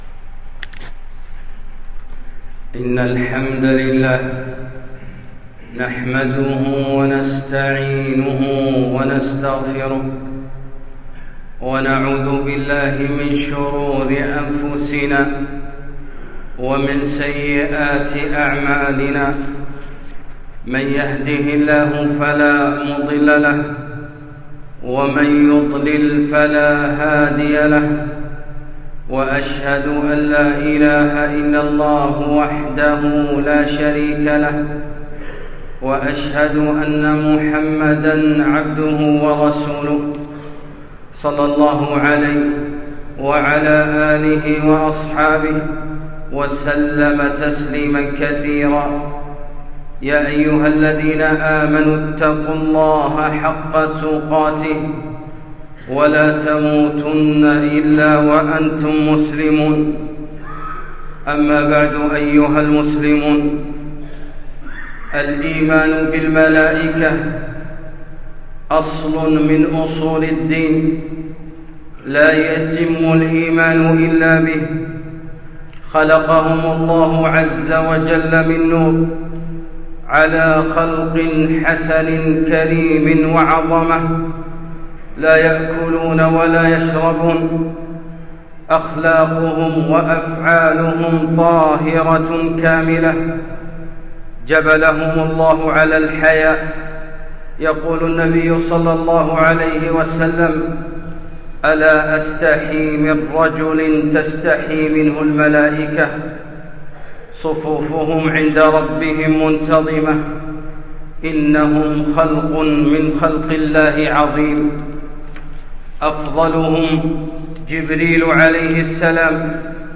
الملائكة - خطبة